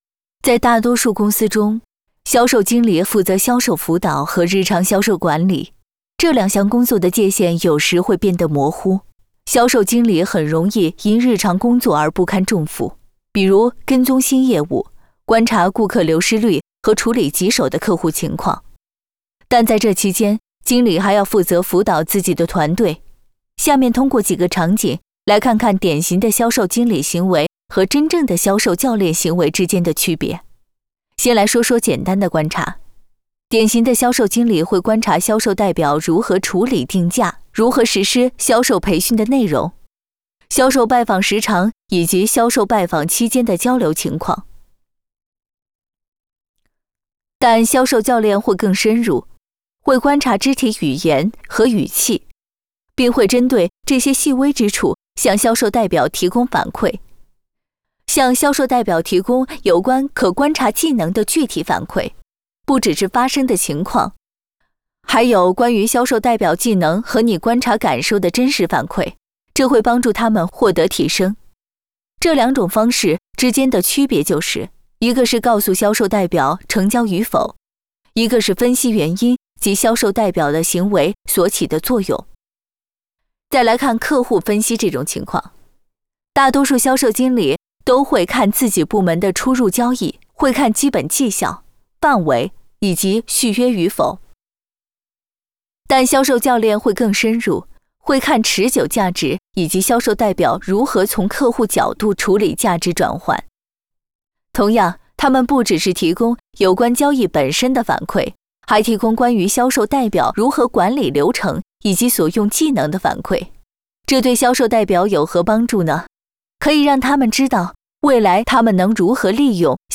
Chinese_Female_018VoiceArtist_8Hours_High_Quality_Voice_Dataset